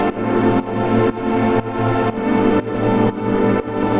Index of /musicradar/sidechained-samples/120bpm
GnS_Pad-dbx1:4_120-A.wav